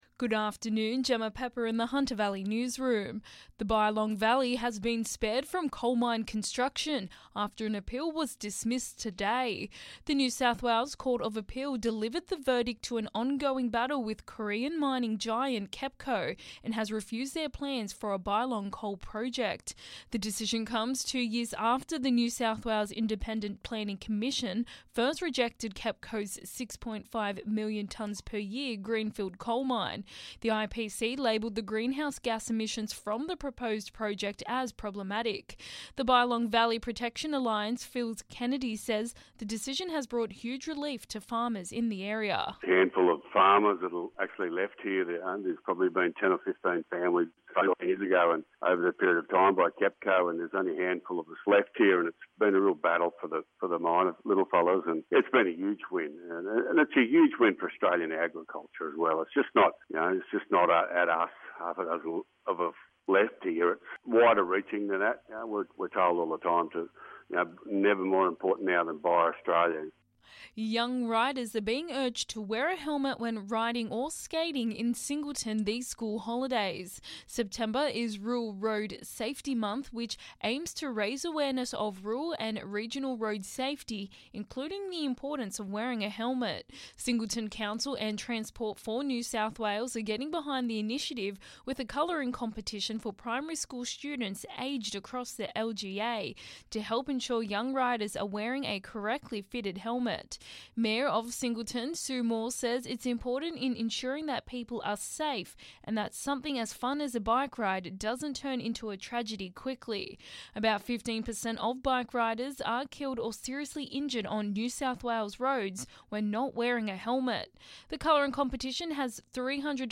Listen: Hunter Local News Headlines 14/09/2021